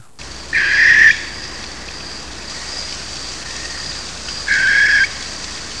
6. Barn Owl
This call can be heard during night migration or when the bird is just out flying during the breading season.
barn_owl.wav